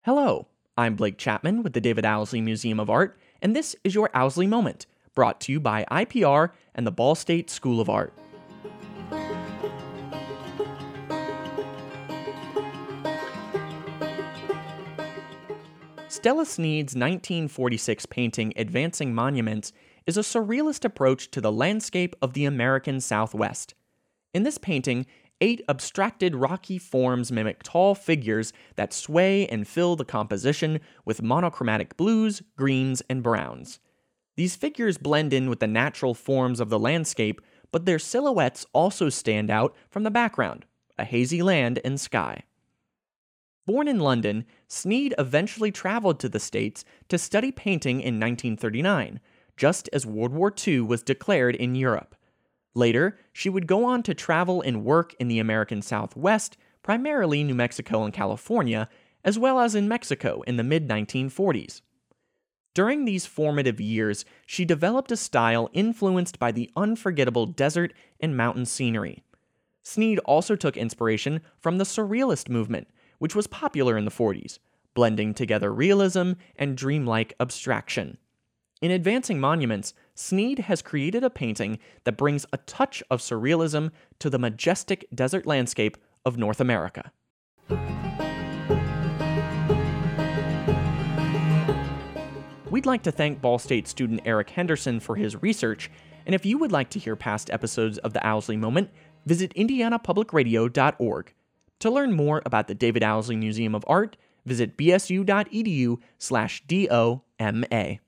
Music used in this episode:
They are produced in collaboration with the David Owsley Museum of Art and Indiana Public Radio and are voiced by Ball State students.